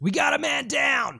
Voices / Male / Man Down.wav